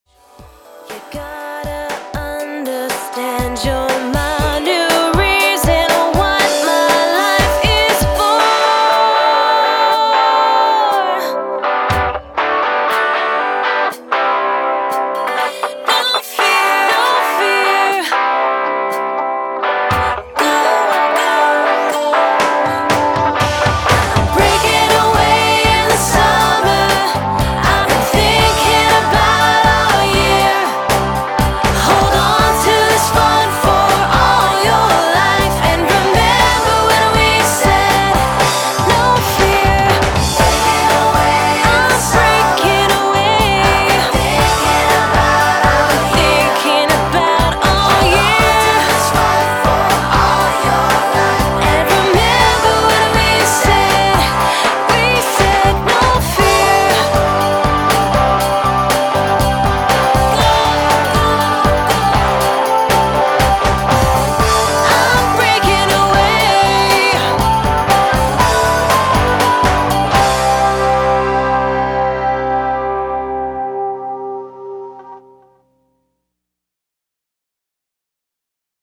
pop artist
With strong rhythms and
energetic power-pop tunes